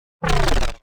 railgun-turret-deactivate-1.ogg